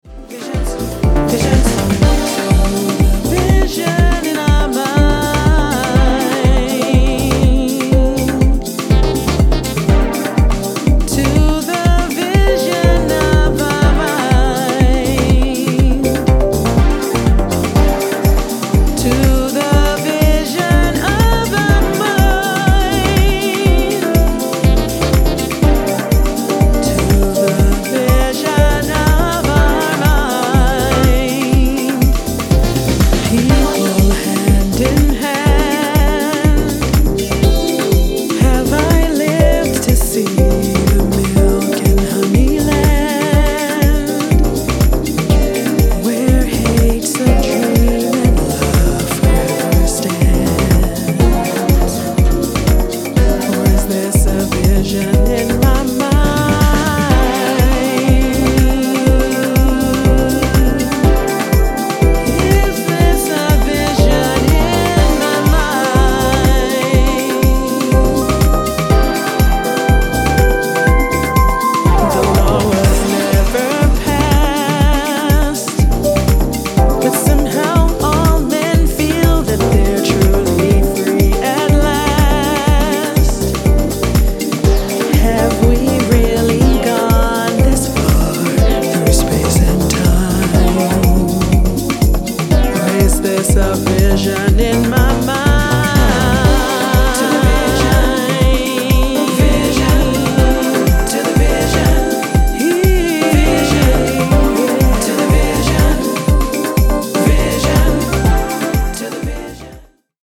positive synths